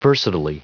Prononciation du mot : versatilely
versatilely.wav